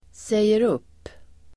Uttal: [sejer'up:]